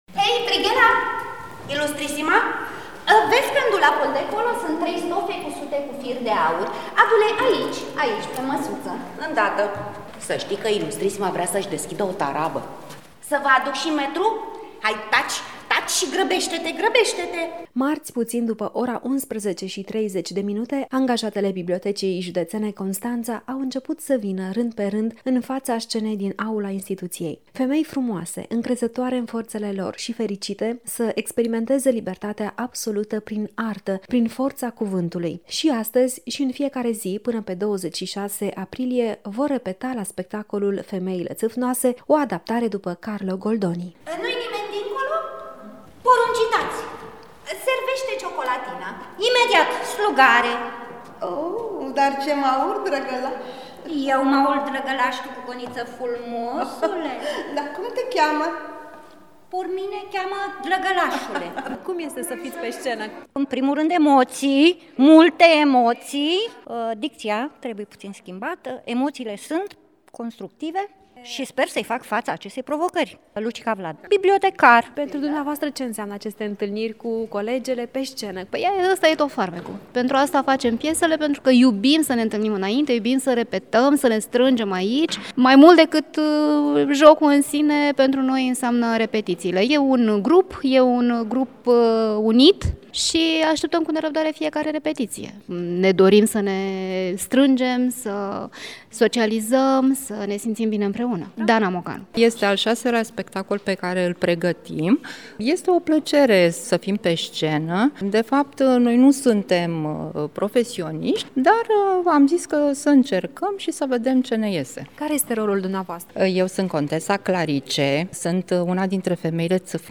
Am fost și noi să le vedem la repetiții și am fost uimiți de talentul, dar mai ales de bucuria acestora atunci când urcă pe scenă. Ne-au mărturisit că atunci când se întâlnesc la repetiții învață nu numai replici, ci și una dintre cele mai frumoase lecții, prietenia adevărată.